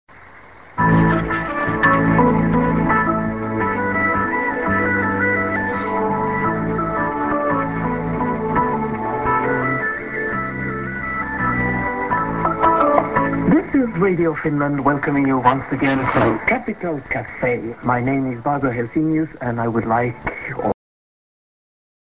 c:　music->ID(women)